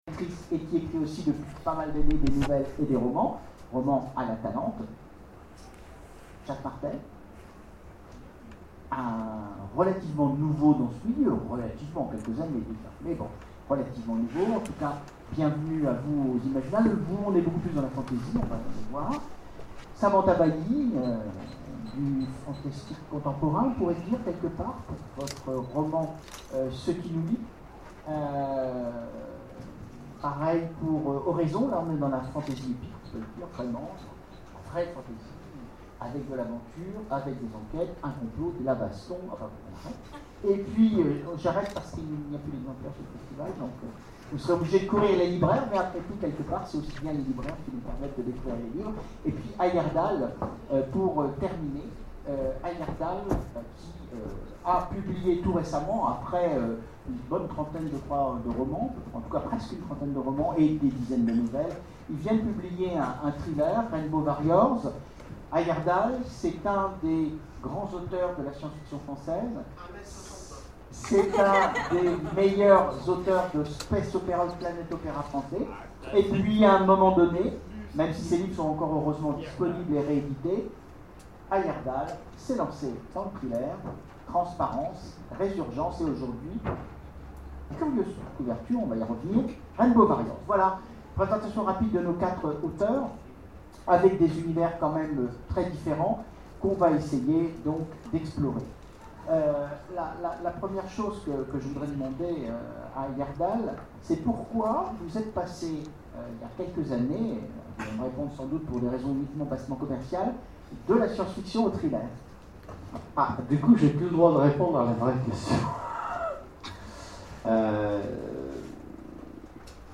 Imaginales 2013 : Conférence Pays imaginaires...